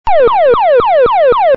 Живые звуки, имитация [39]